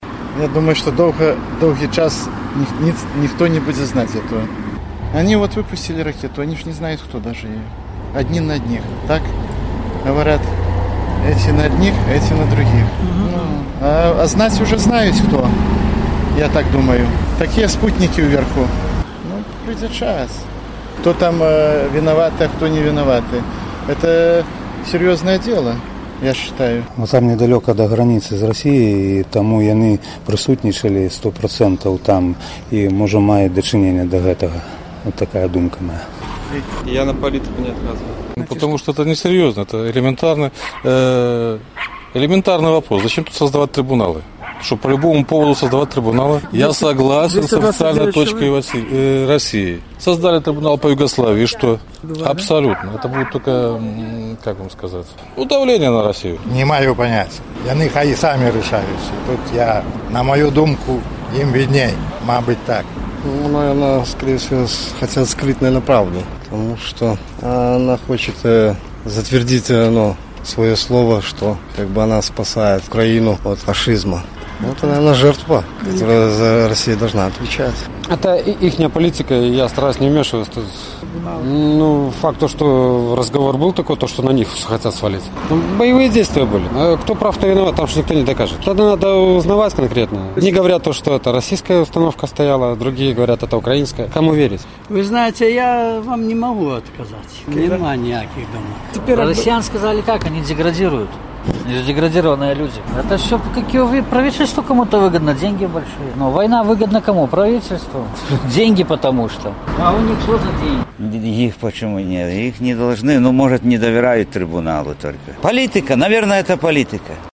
Чаму Расея адмаўляецца ад міжнароднага трыбуналу па зьбітым малайзійскім «Боінгу»? З такім пытаньнем наш карэспандэнт зьвяртаўся да гарадзенцаў.